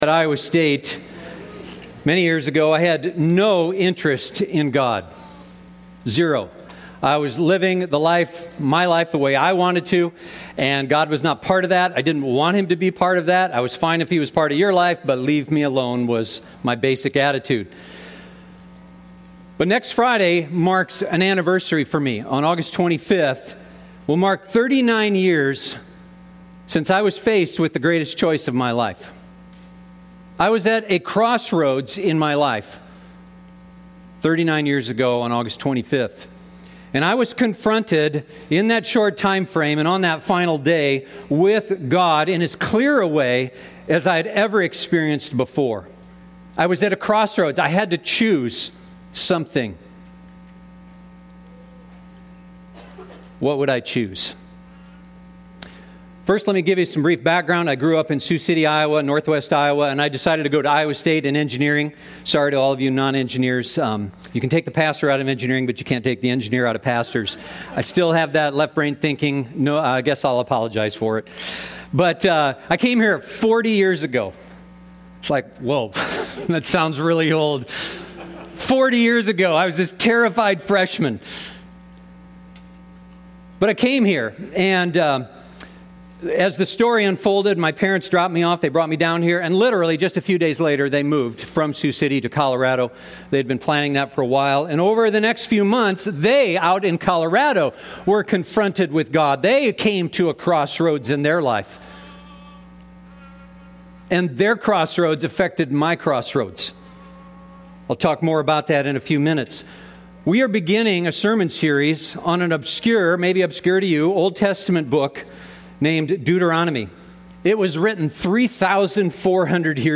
We are beginning a sermon series for this fall on a book from the Bible named Deuteronomy.